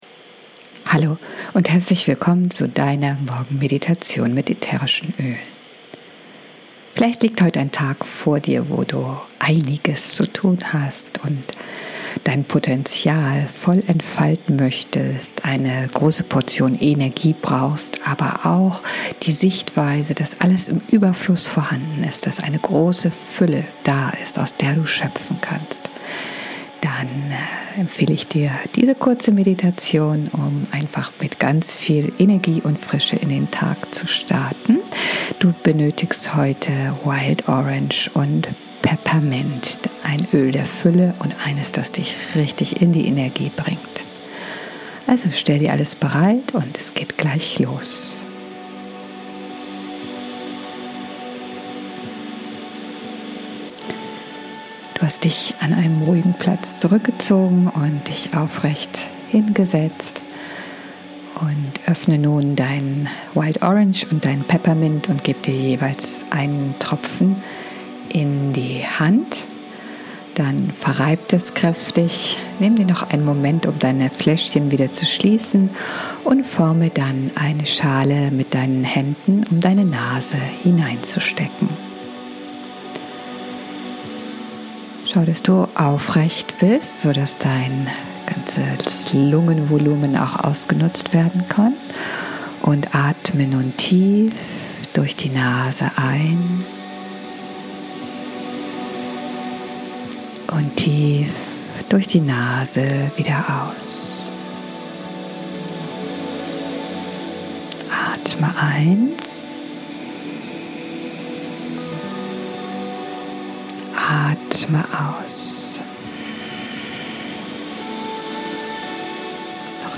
#2 Morgenmeditation - Energie & Fülle für den Tag